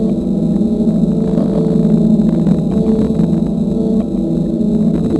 A320_flapmotor.wav